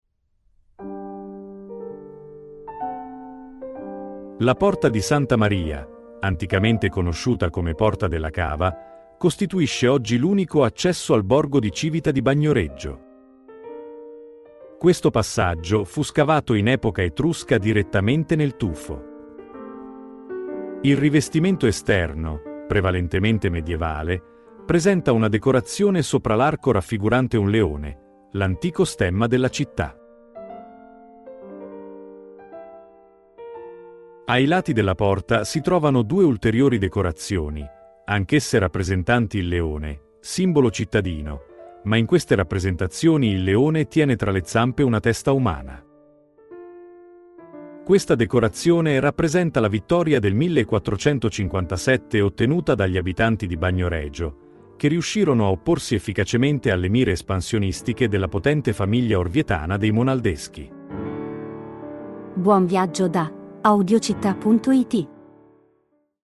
Audioguida Civita di Bagnoregio - La Porta - Audiocittà